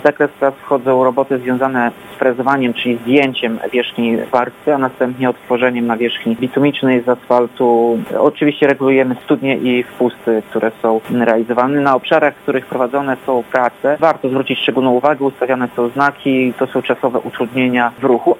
– Prace nie powinny powodować większych utrudnień w ruchu, jednak prosimy kierowców o ostrożność – mówi Tomasz Andrukiewicz – prezydent Ełku.